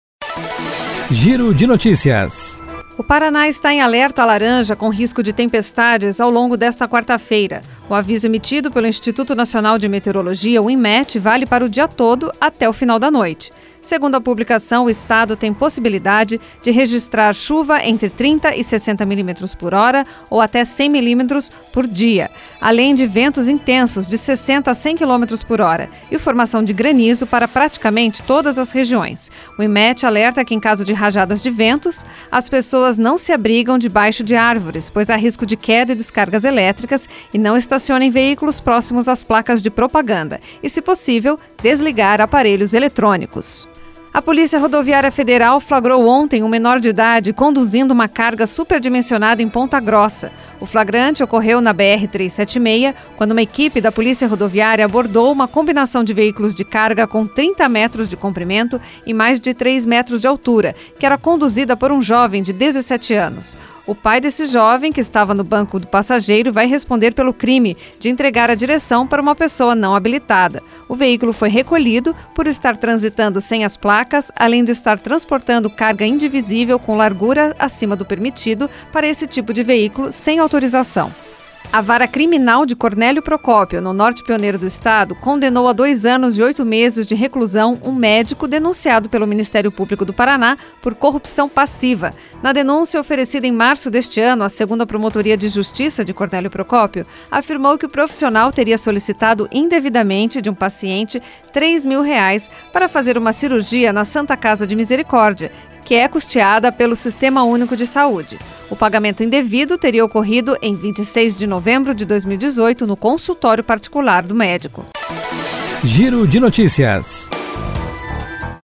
Giro de Notícias Tarde COM TRILHA